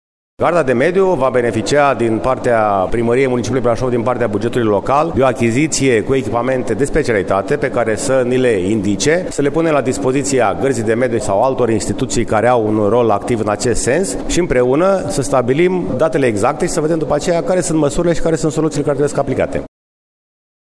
Anunţul a fost făcut, ieri, de primarul George Scripcaru, în cadrul ședinței privind calitatea aerului în Brașov, o ședință destul de tensionată: